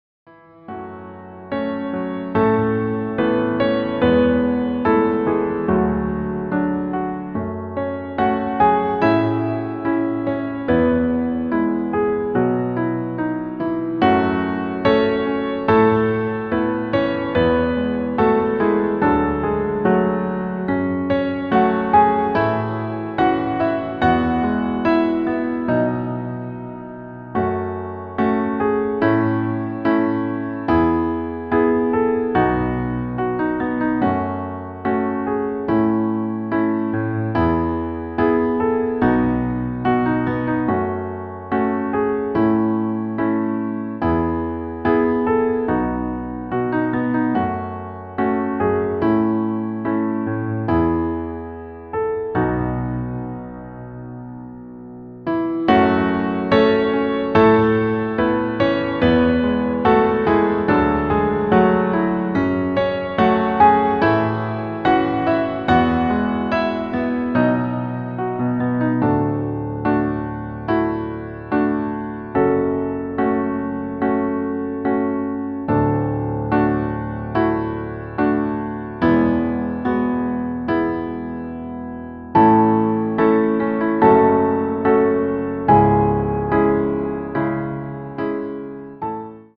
• Tonart: Am, Em, F#m
• Das Instrumental beinhaltet NICHT die Leadstimme